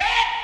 Vox
Scary James Brown.wav